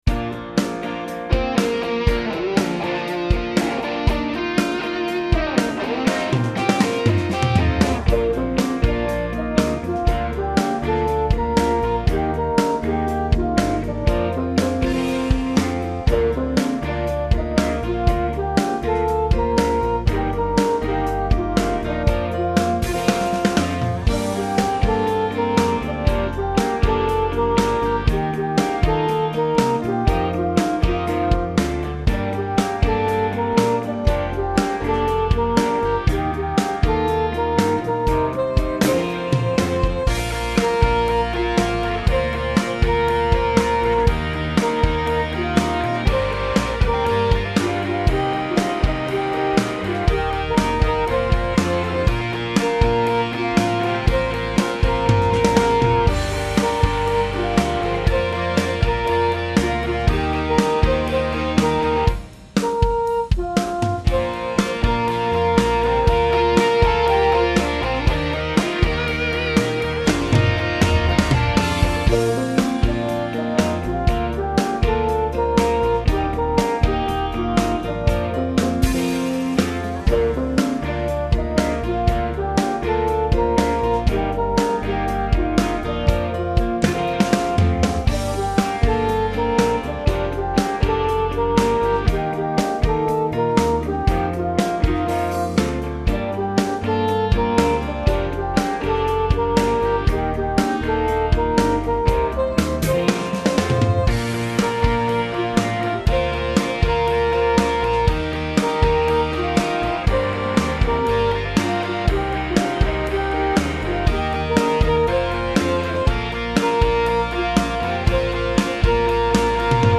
a rock beat
I’ve done a rock backing: